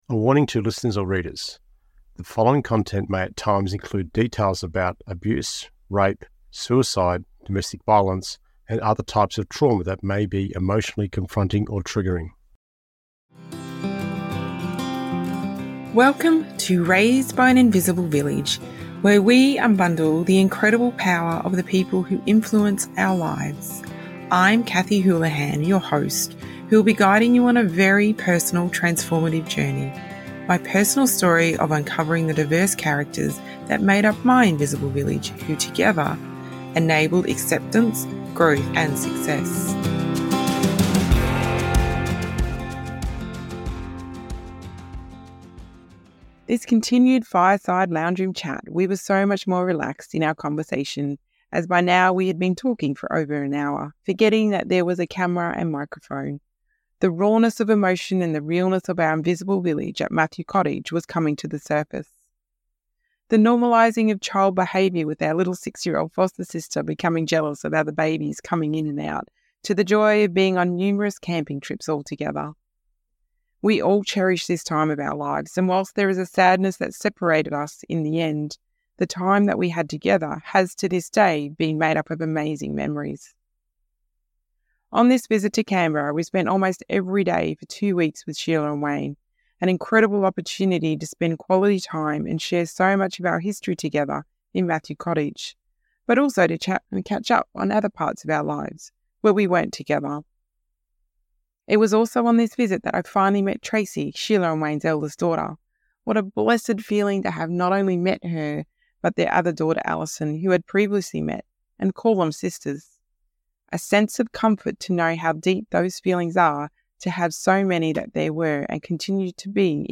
we hear a more relaxed and heartwarming conversation that transports us to a realm where time seems to stand still and where the raw emotions of a close-knit invisible village come to life.
The beauty of this conversation lies in its authenticity.